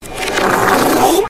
Reversed kracc bacc fard
reversed-kracc-bacc-fard.mp3